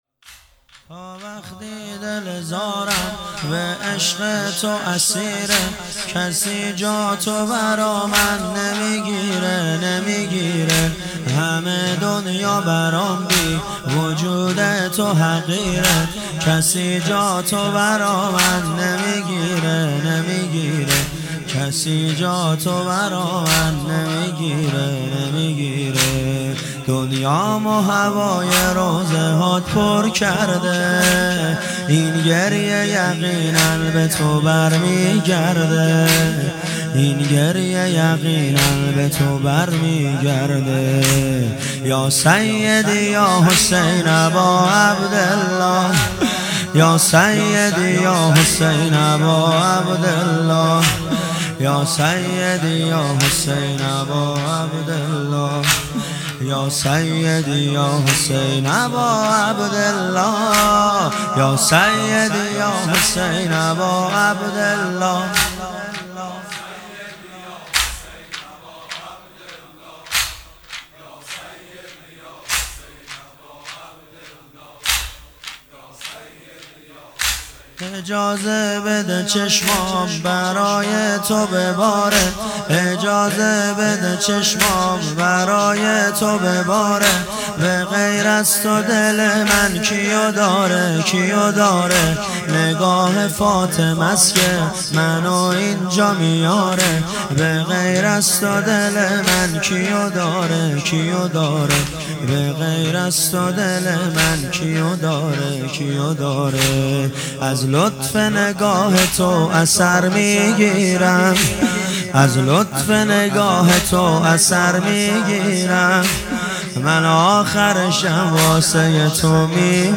محرم1400
محرم1400 شب هشتم